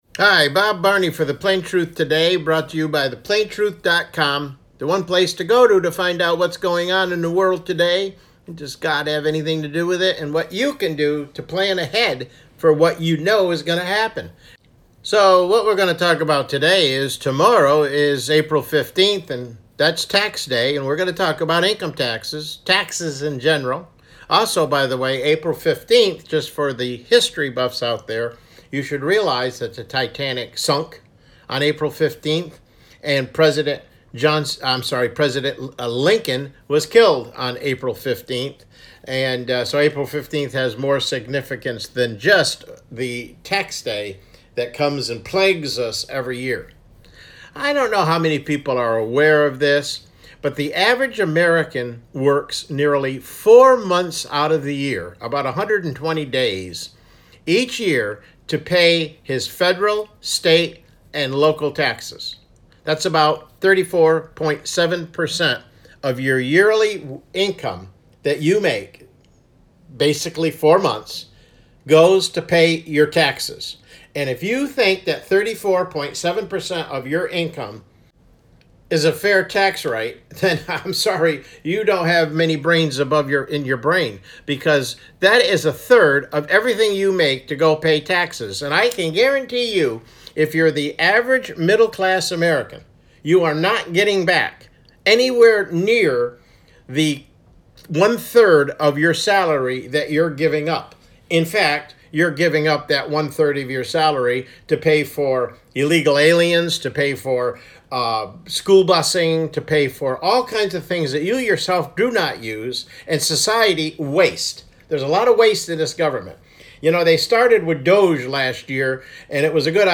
CLICK HERE TO LISTEN TO THE PLAIN TRUTH TODAY MIDDAY BROADCAST: It’s Tax Time…